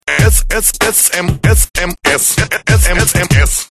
SMS рингтоны